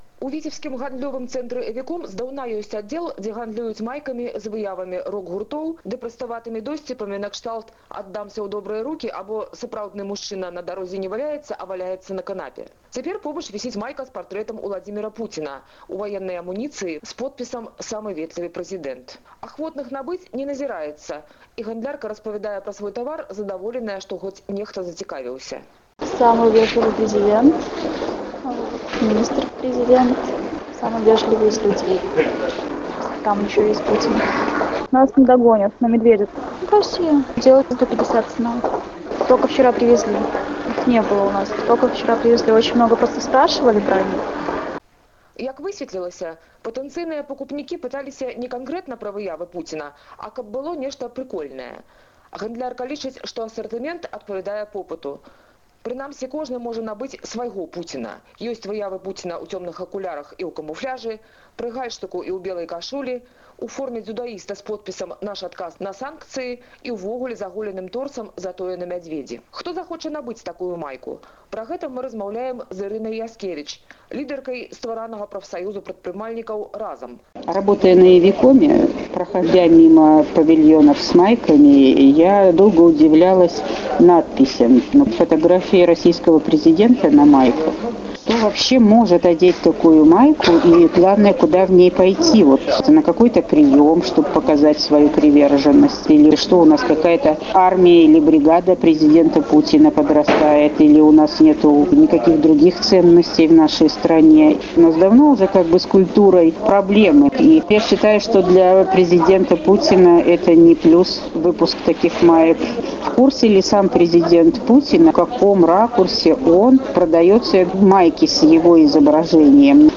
Рэпартаж зь Віцебску і Полацку (ФОТА)